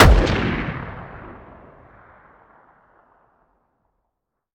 weap_br1_fire_plr_atmo_ext1_04.ogg